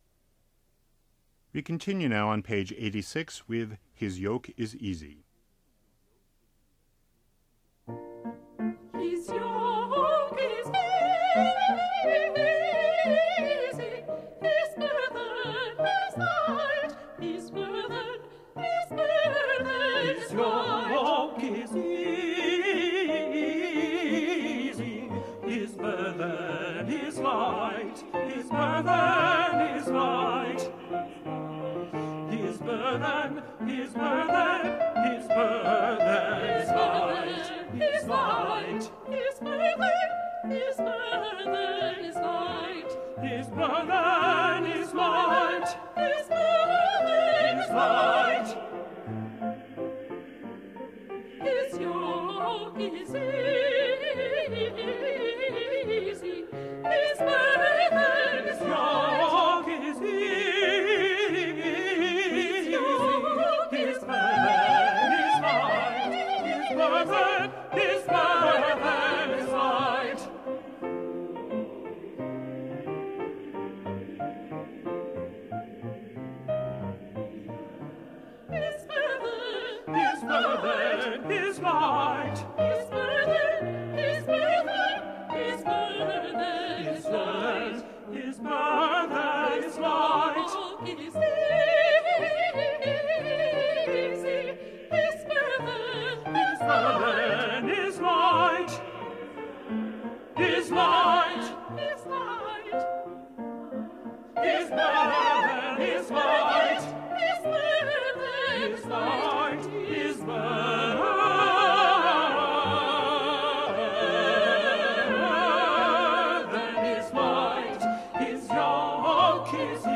They are divided into two sections with either Alto and Bass together  or  Soprano and Tenor – since in each case the parts are well separated and tonally different you should be able to hear your part fairly clearly.
Soprano/Tenor